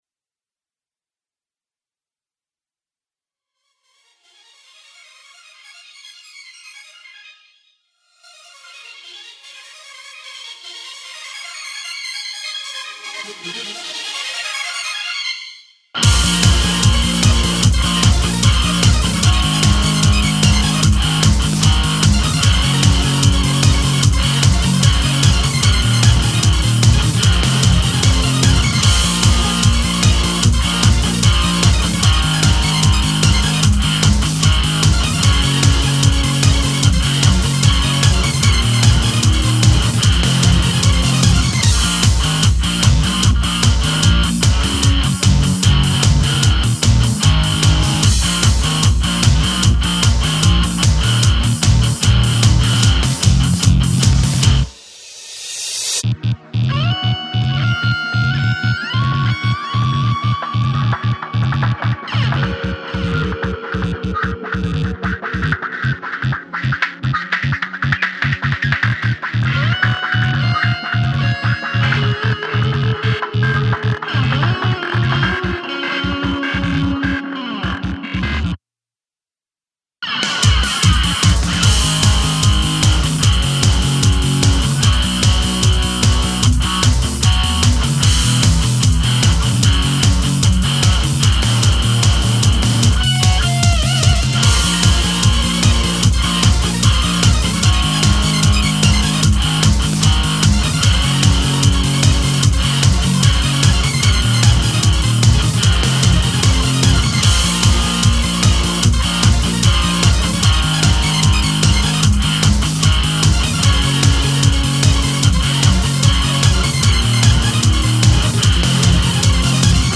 オケMP3ファイル
デジタリックなハードロック曲です。